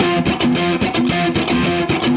AM_HeroGuitar_110-F02.wav